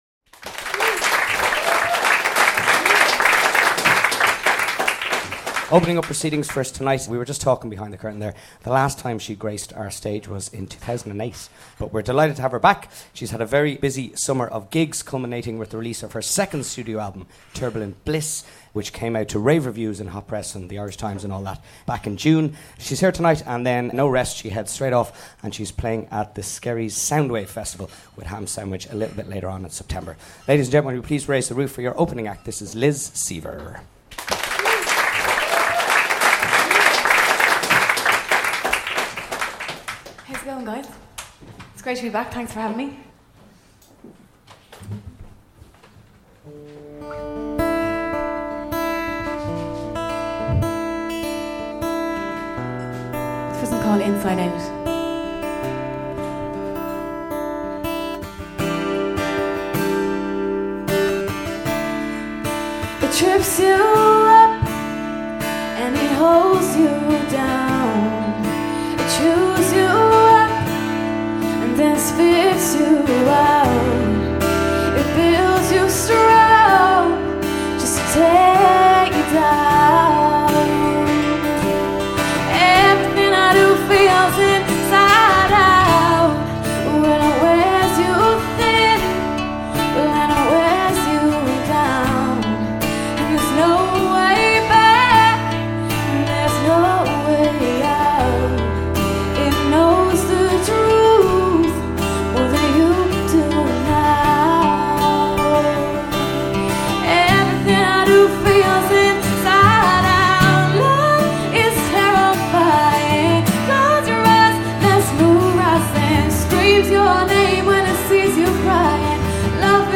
plays a few acoustic songs and chats to us